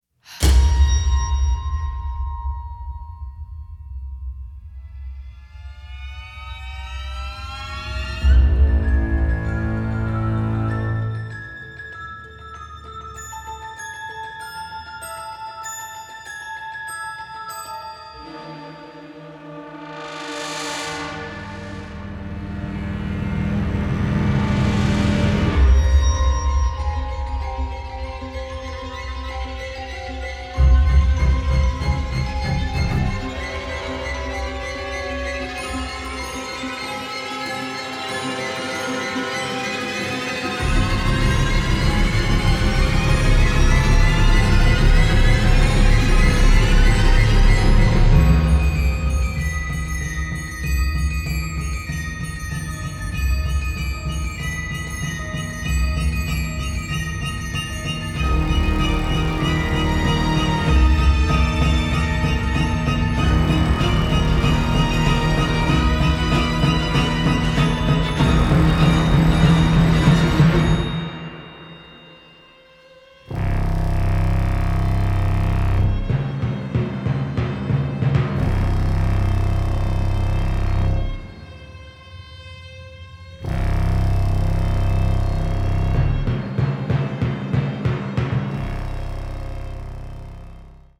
conducts a large orchestra recorded in Los Angeles.